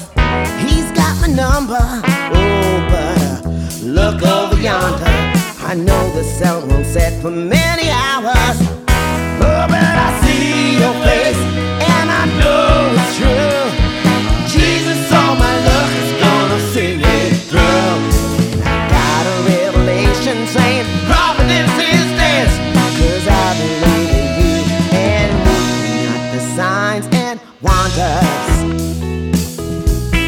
Véritable tempête vocale
R&B Soul